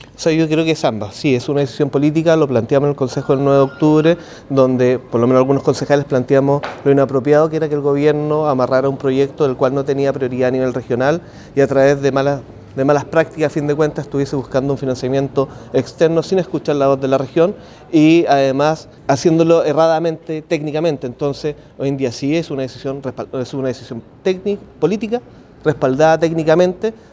El concejal del Partido Republicano, José Piña, ante los cuestionamientos, indicó que la votación fue de índole política, pero con respaldo técnico.